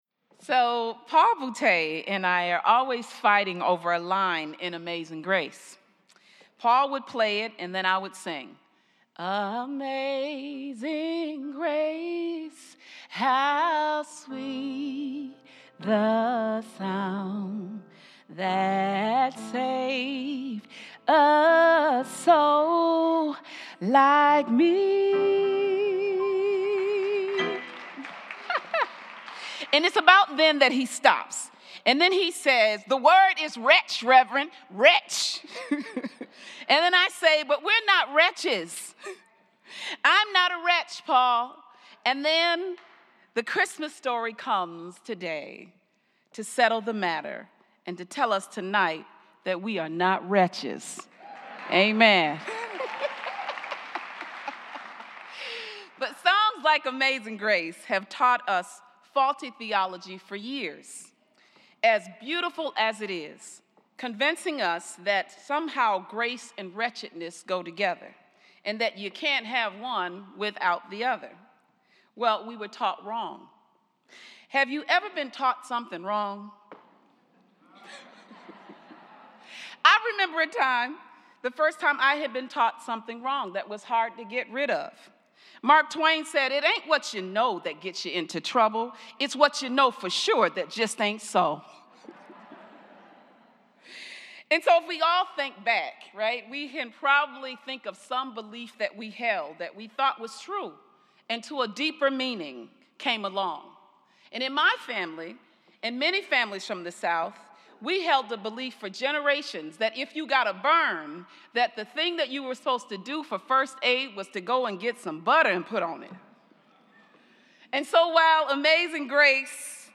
Christmas Eve Mass